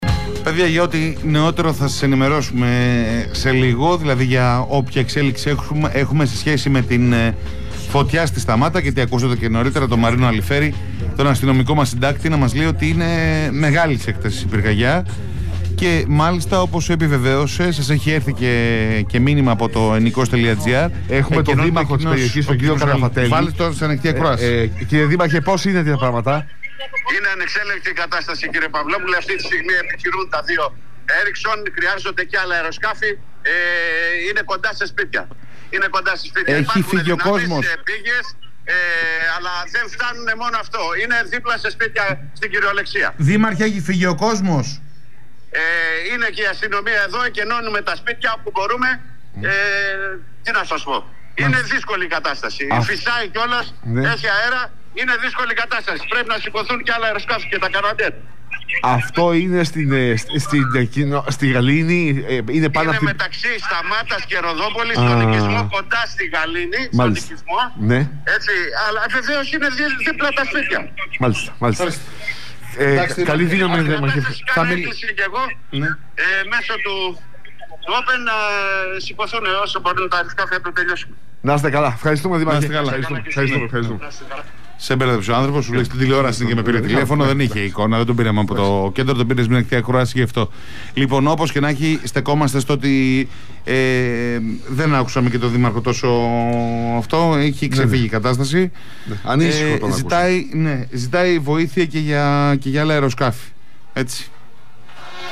Φωτιά στη Σταμάτα - Δήμαρχος Διονύσου στον Realfm: Είναι ανεξέλεγκτη η κατάσταση